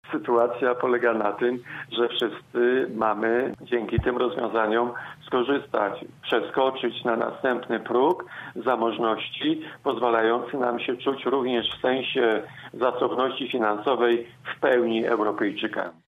Dziś w „Rozmowie po 9” program zachwalał Jacek Kurzępa, poseł PiS: